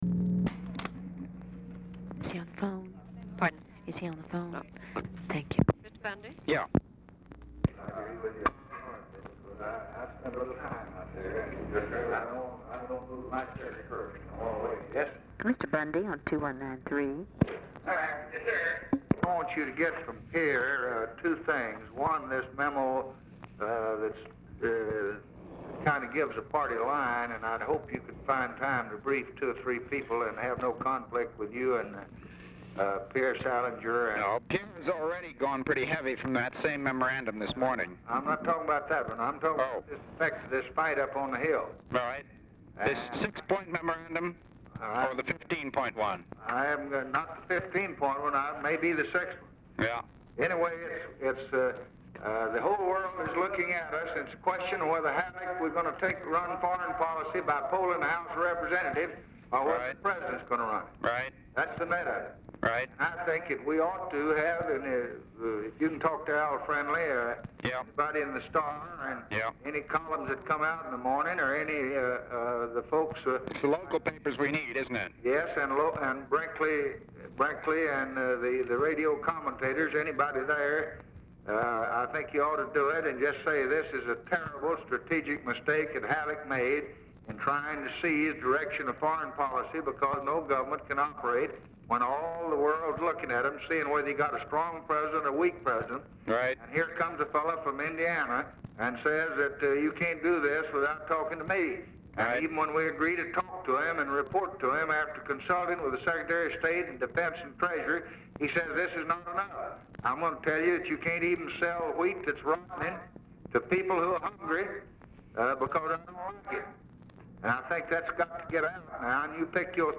Telephone conversation # 675, sound recording, LBJ and MCGEORGE BUNDY, 12/23/1963, 3:01PM | Discover LBJ
BRIEF OFFICE CONVERSATION PRECEDES CALL
Format Dictation belt
Location Of Speaker 1 Oval Office or unknown location